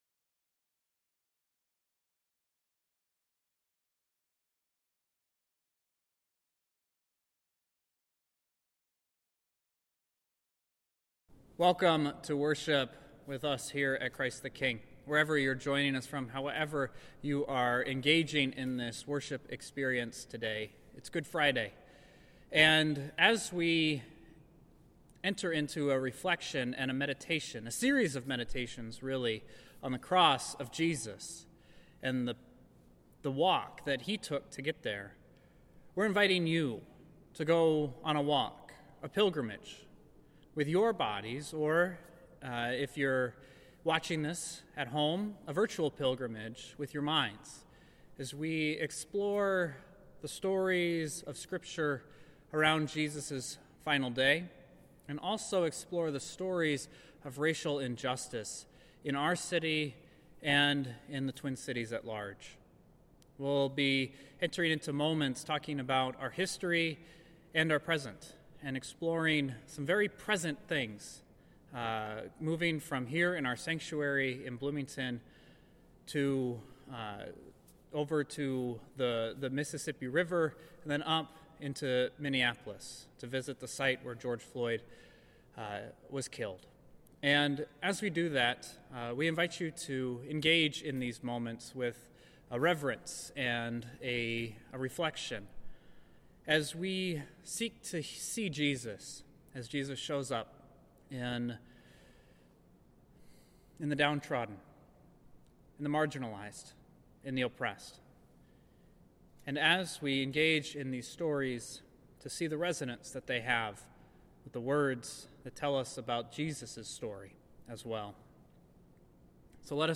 Each of the 7 stations begins with a passage of scripture, and continues with Meditations & Contemporary Connections. In between each station, there is some more music to accompany you on your journey.